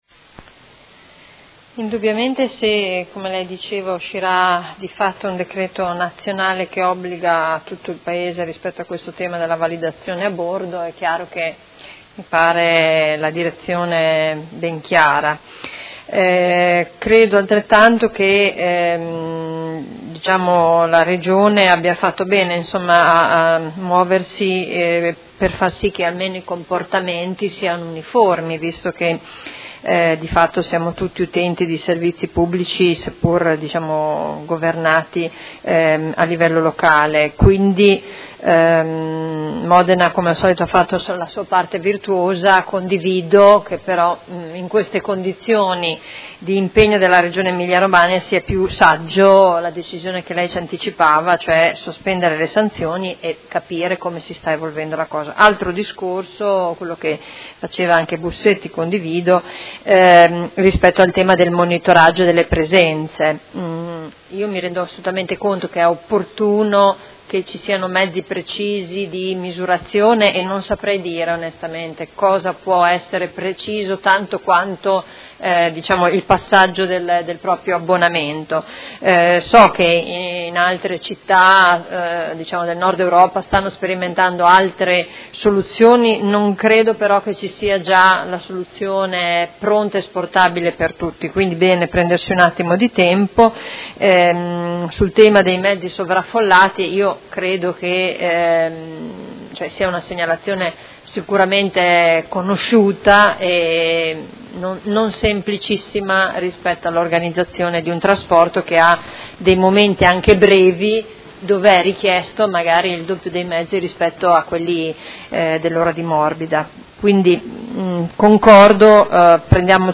Seduta del 27/10/2016 Dibattito. Interrogazione della Consigliera Arletti (P.D.) avente per oggetto: Multe ad abbonati SETA – occorre rivedere le regole di sanzionamento abbonati.